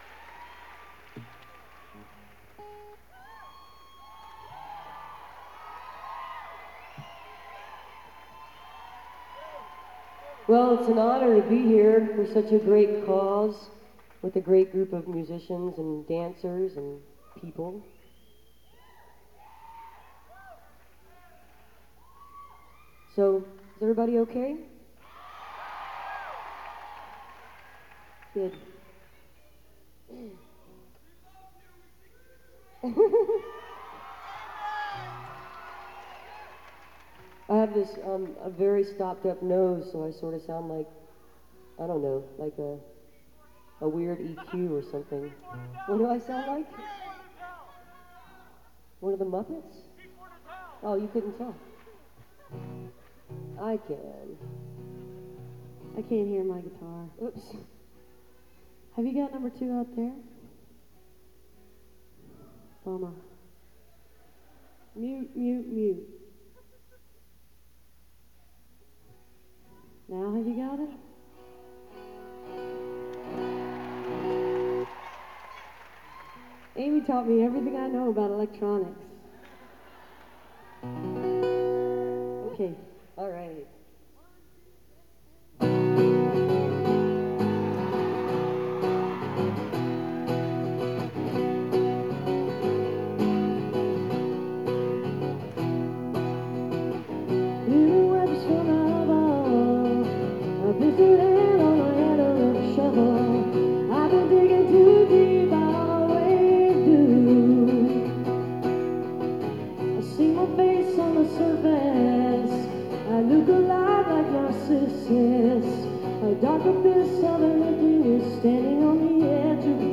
(acoustic duo)